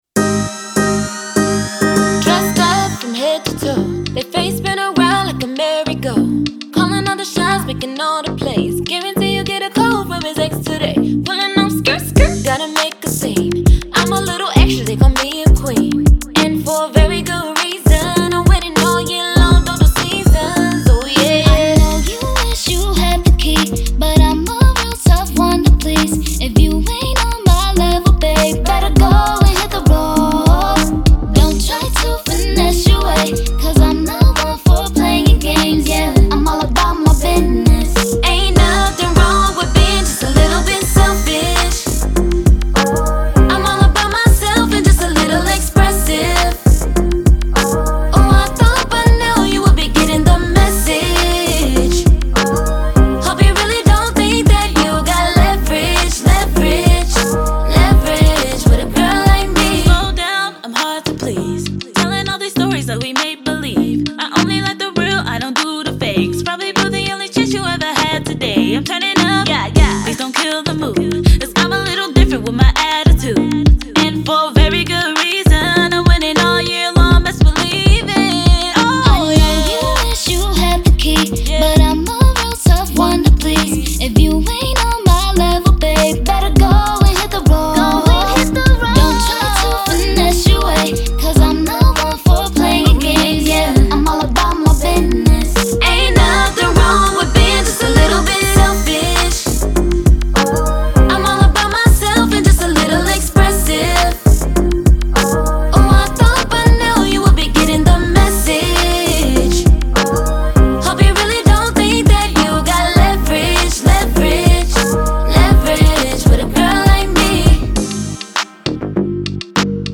RnB
AN UPBEAT R&B / Pop TRACK